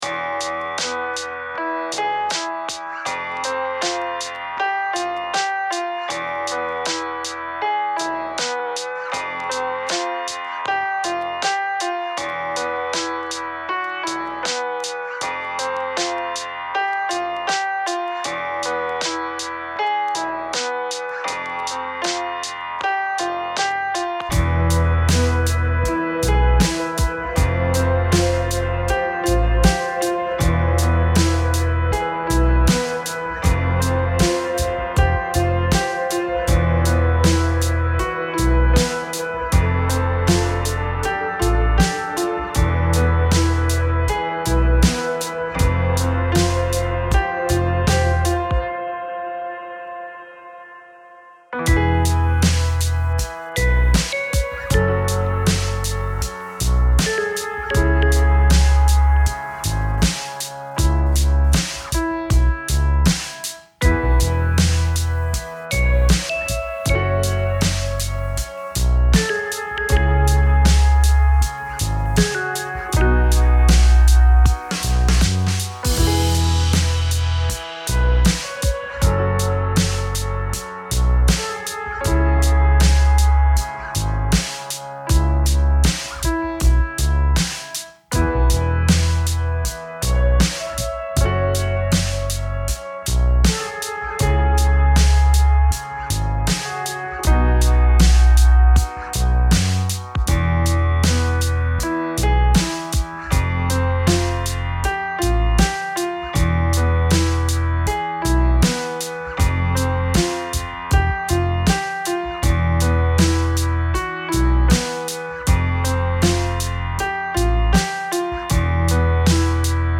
Another Dope Jam